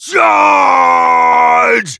bull_ulti_vo_02.wav